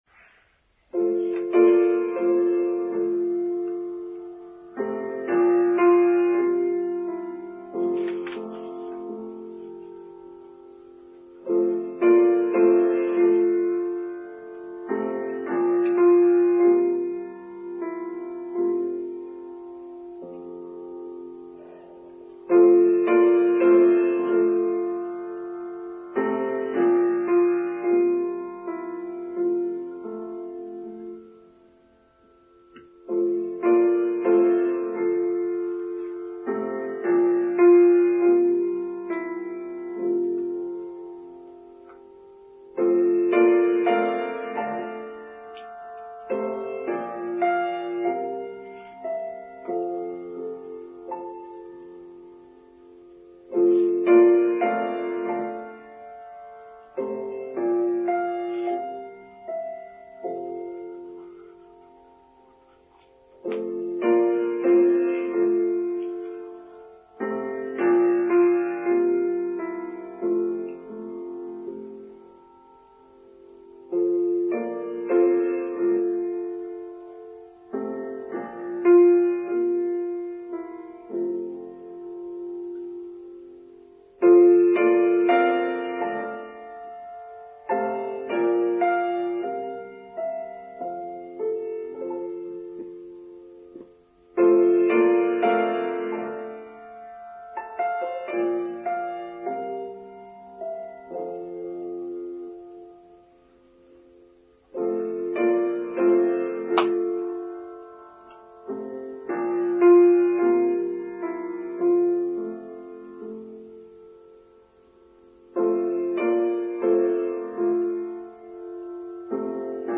Accuracy: Online Service for Sunday 22nd January 2023